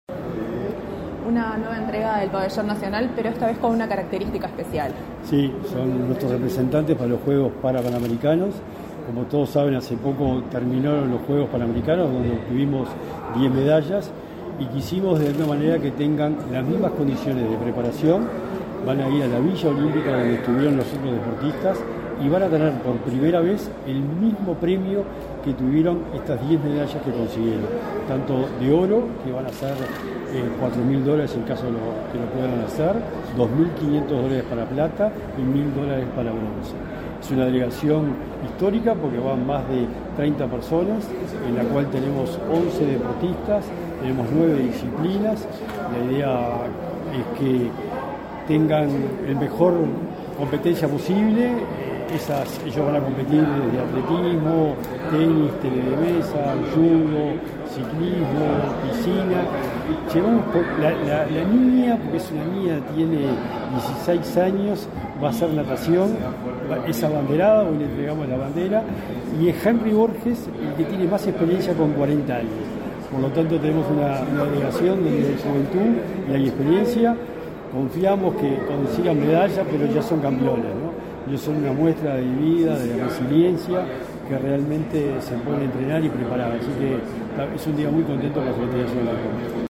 Declaraciones del secretario nacional del Deporte, Sebastián Bauzá
Tras el evento el secretario de la SND, Sebastián Bauzá, realizó declaraciones a Comunicación Presidencial.